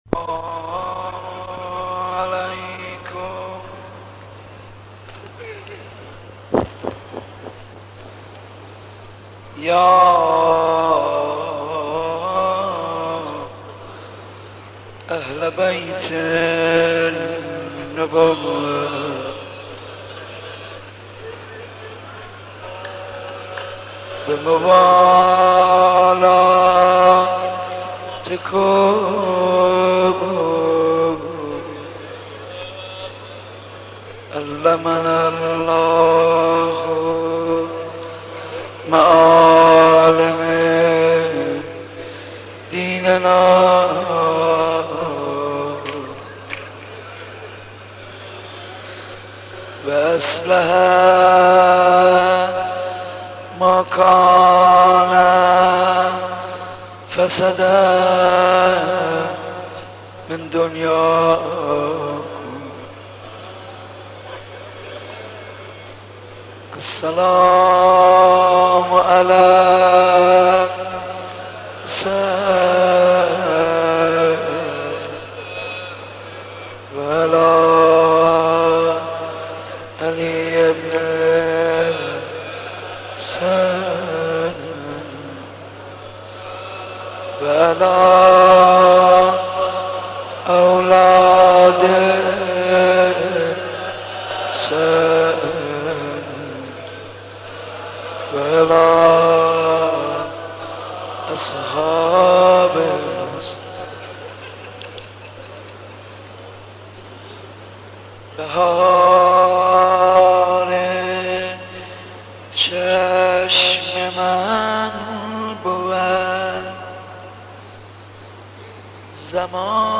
shabe 05 moharram 78 ark.mp3